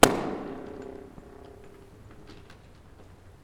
ballon flutter pop reverb tunnel sound effect free sound royalty free Sound Effects